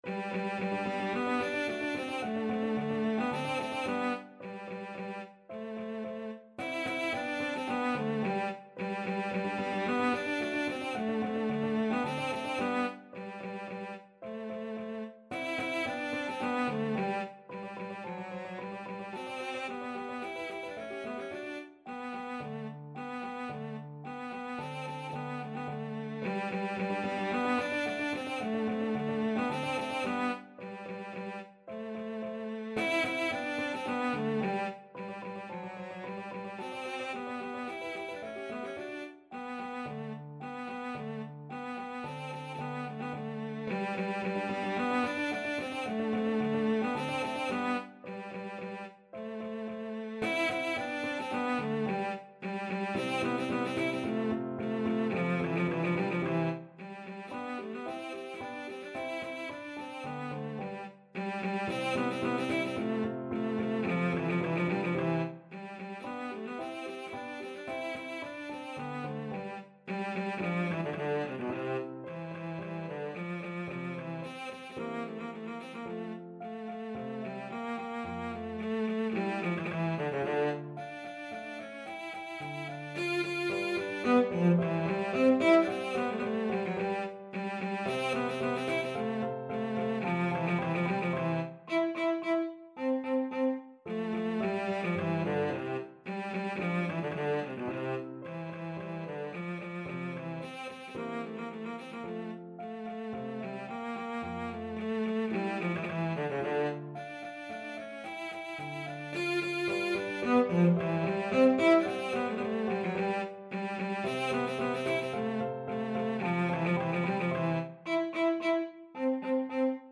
Cello
Vivace assai =110 (View more music marked Vivace)
G major (Sounding Pitch) (View more G major Music for Cello )
Classical (View more Classical Cello Music)